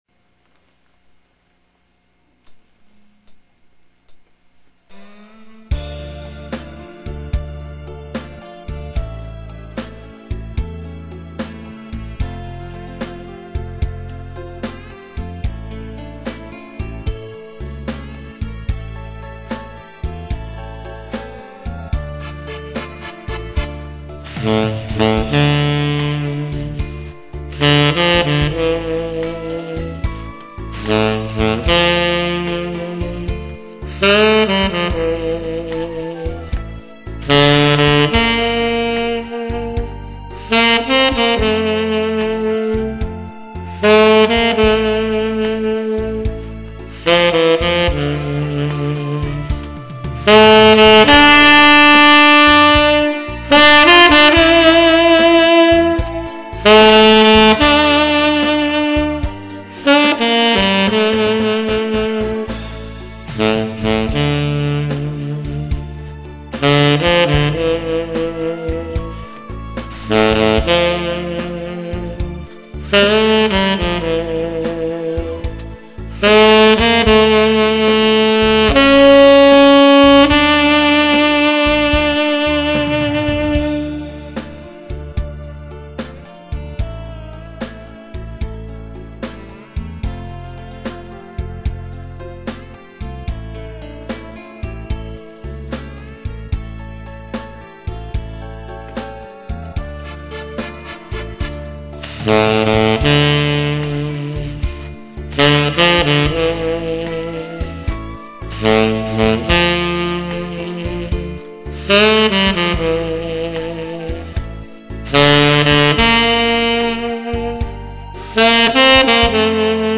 테너연주